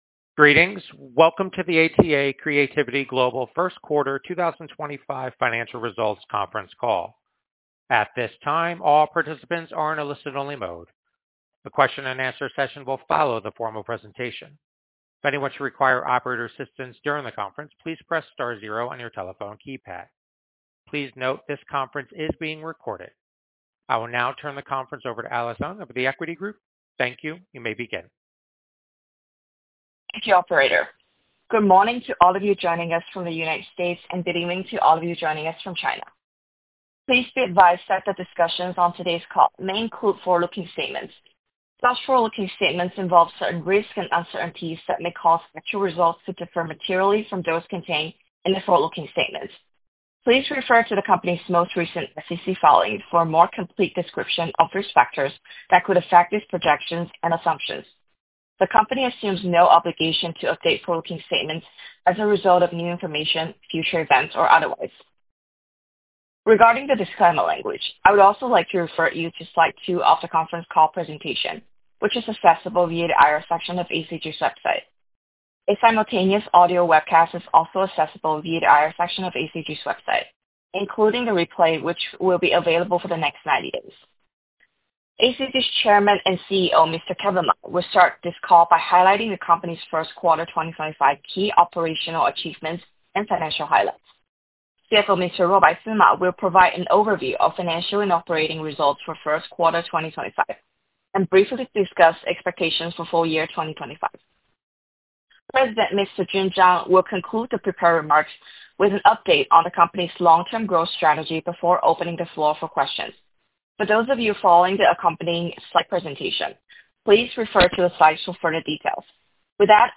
Q1 Fiscal Year 2025 Earnings Conference Call